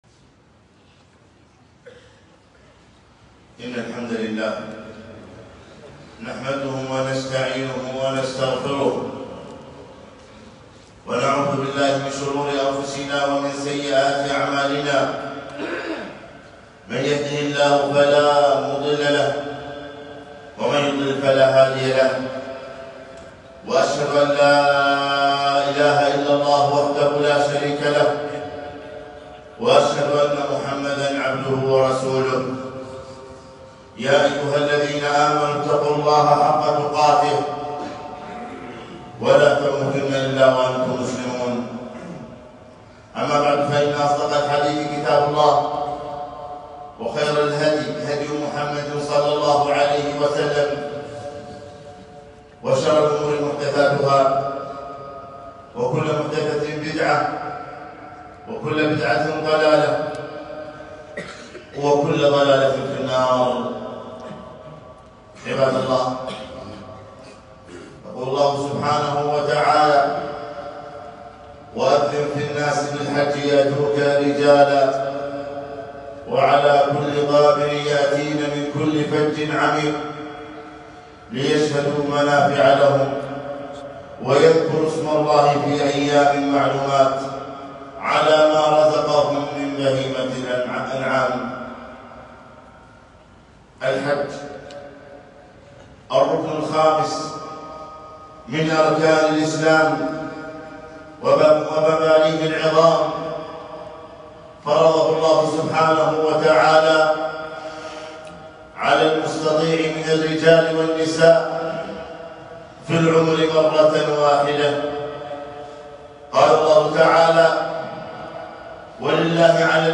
خطبة - ( تعجلوا الحج )